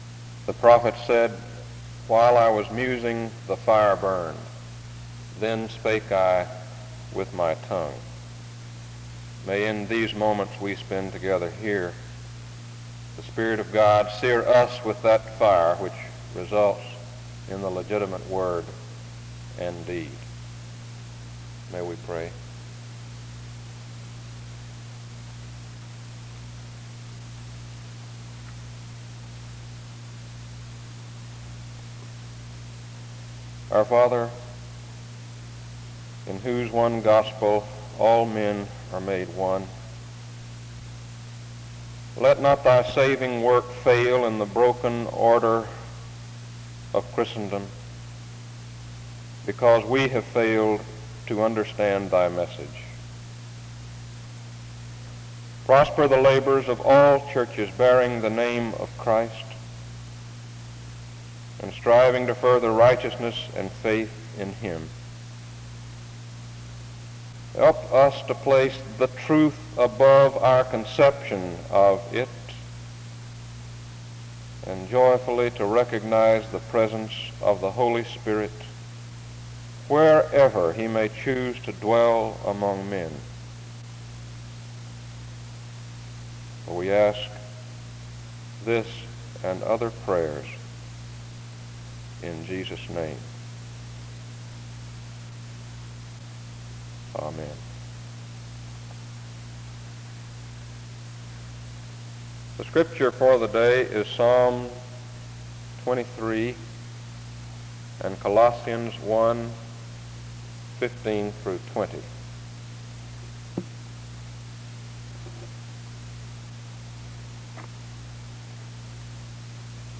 The service begins with prayer from 0:00-1:37. The Scripture reading, Psalm 23 and Colossians 1:15-20, is read from 1:42-4:20. Music plays from 4:25-4:57. An introduction to the speaker is given from 5:24-8:02.